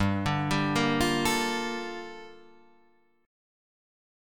G Minor 9th